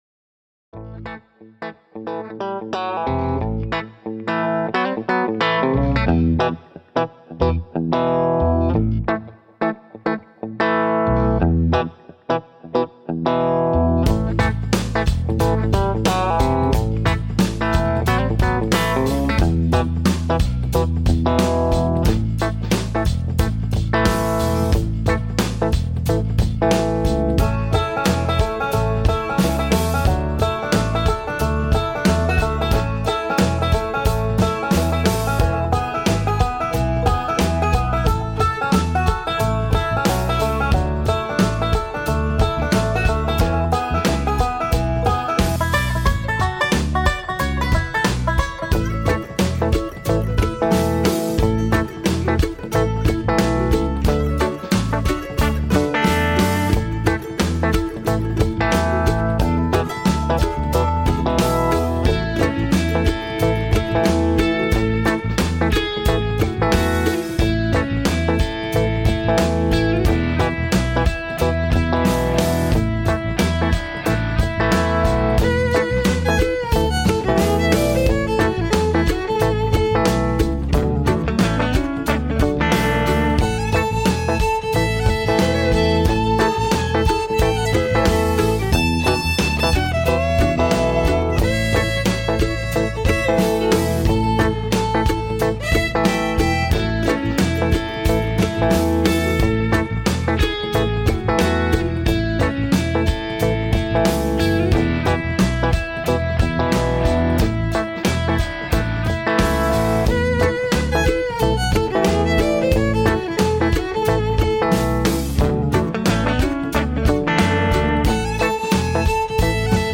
Talk Show Episode, Audio Podcast, PRQ Homesteading Expanded and Defending the Homestead, How to Beat Foreclosure at Bankruptcy Confirmation on , show guests , about Defending the Homestead,Foreclosure and Bankruptcy Strategies,Foundation of Resilience,Grounding and Mental Clarity,systemic issues,Property Tax Fraud,Foreclosure,Bankruptcy Confirmation, categorized as Education,Entertainment,Politics & Government,Local,National,Society and Culture,Spiritual,Access Consciousness